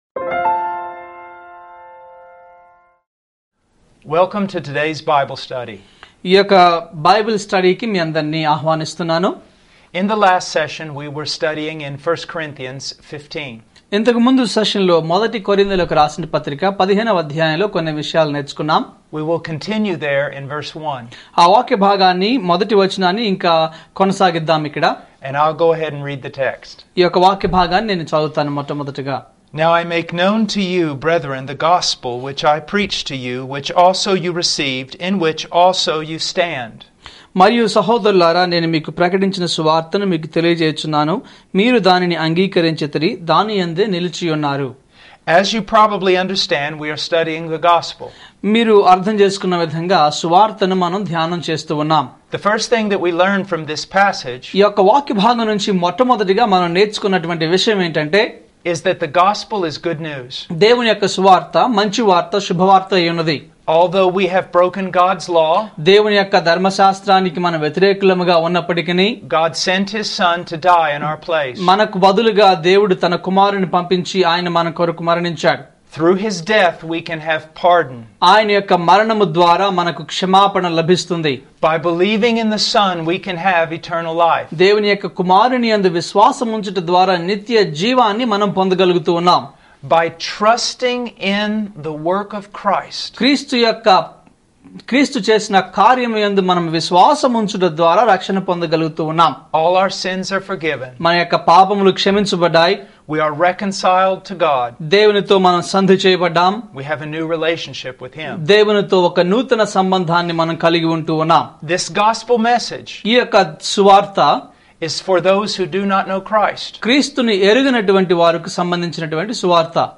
ఫుల్ సేర్మోన్ (FS)